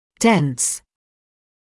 [dens][дэнс]плотный; густой, непроницаемый